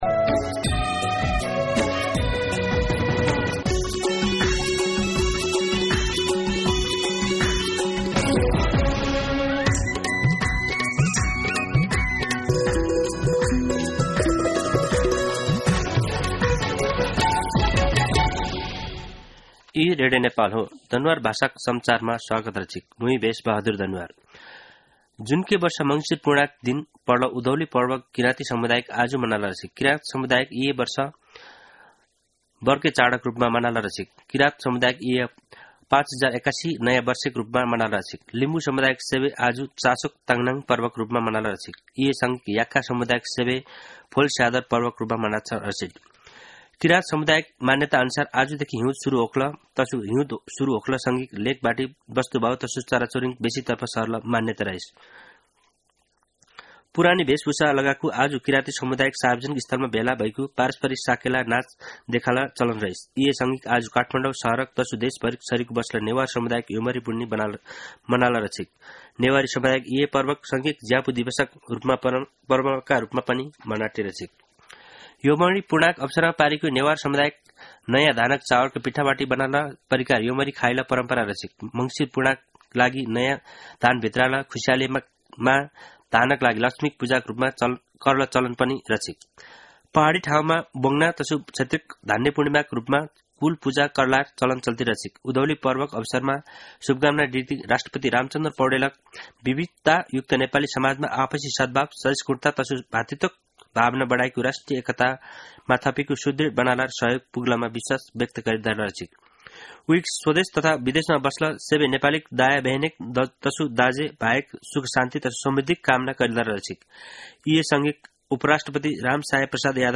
दनुवार भाषामा समाचार : १ पुष , २०८१
Danuwar-News.mp3